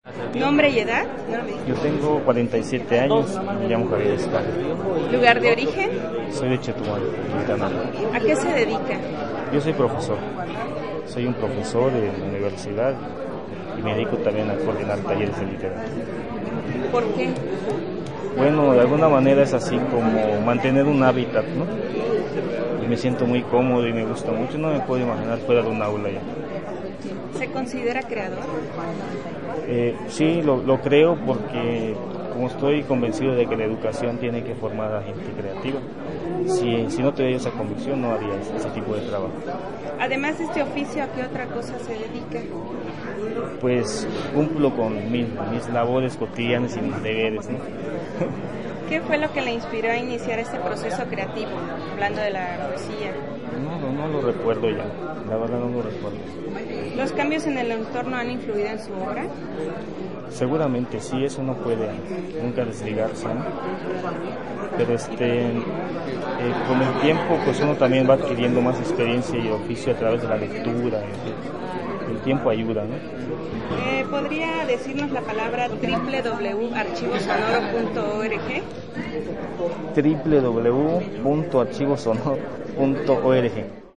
Entrevista
Lugar de la grabación: Auditorio del Centro Cultural de Chiapas Jaime Sabines, Tuxtla Gutierrez, Chiapas; Mexico.
Equipo: Minidisc NetMD MD-N510, micrófono de construcción casera (más info)